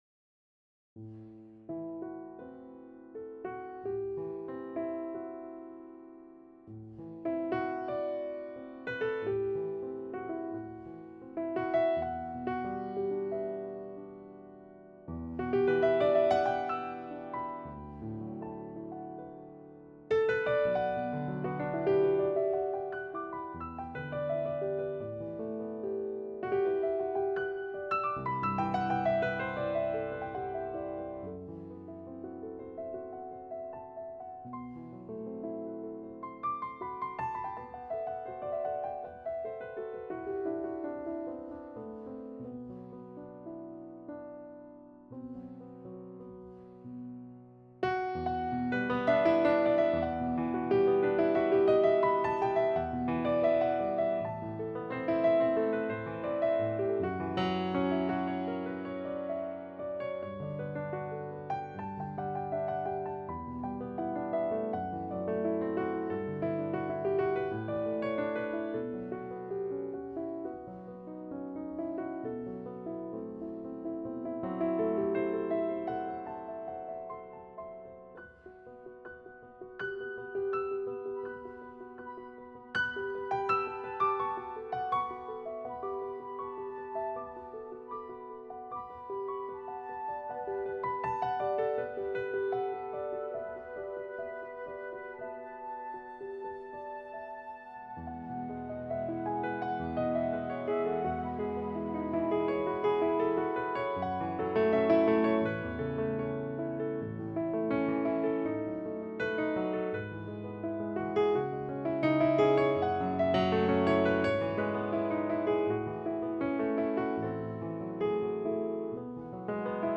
All of the tracks that follow are (mostly) piano improvisations, each performed with only one pass and having no edits or other alterations following the performances.